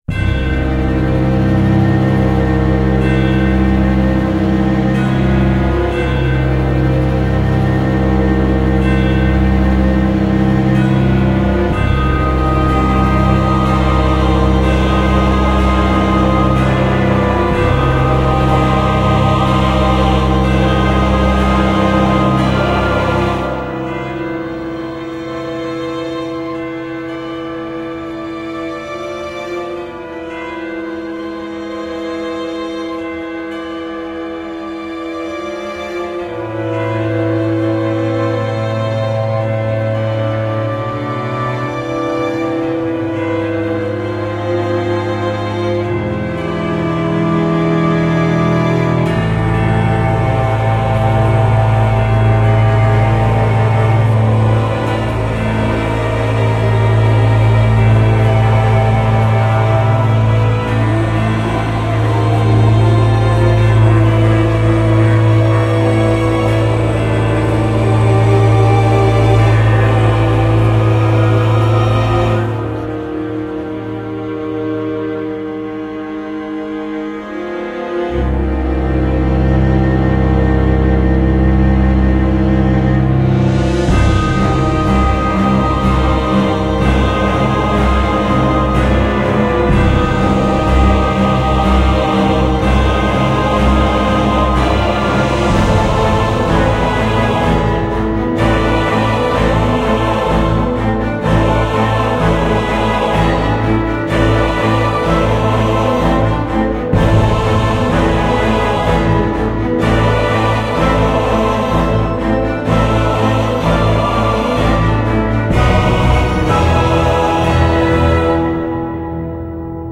Жанр: Score
Исполнение исключительно инструментальное.